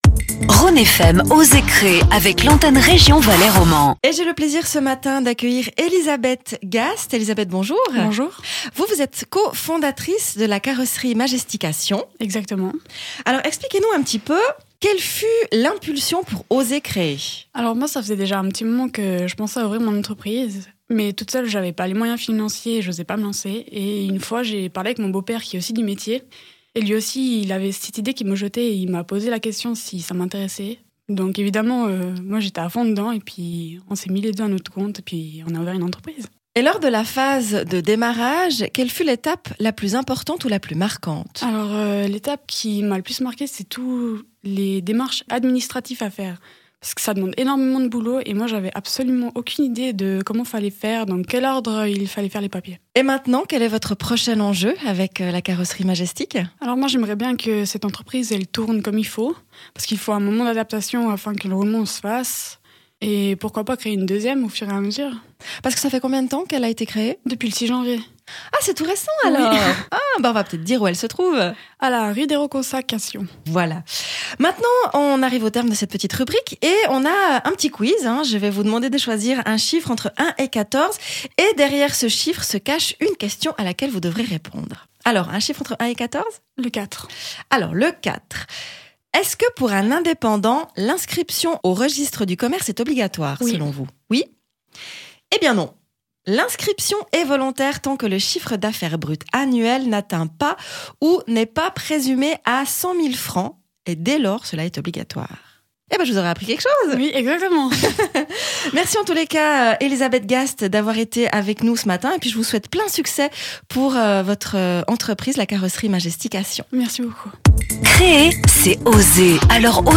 Des interviews de deux minutes consacrées à l’esprit entrepreunarial pour mieux connaître une région et son économie.
Ecoutez ou réécoutez la rubrique « Oser créer » diffusée sur Rhône FM le vendredi à 11h50.